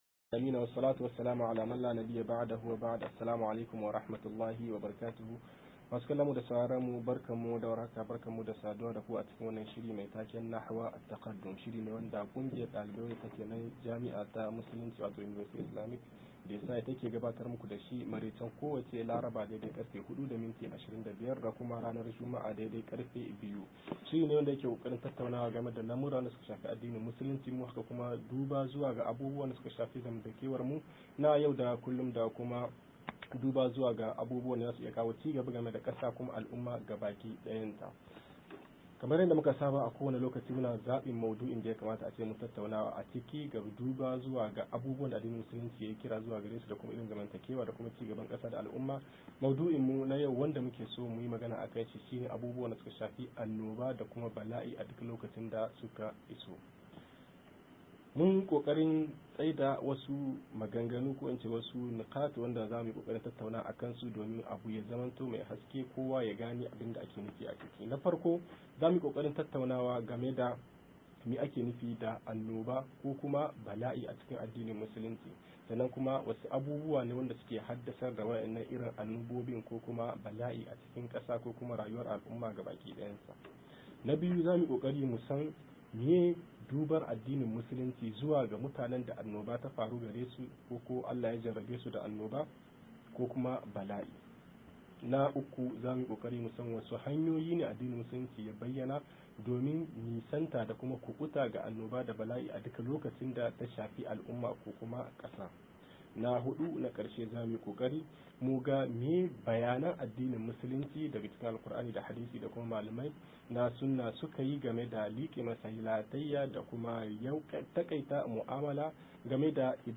66-bayani kan corona - MUHADARA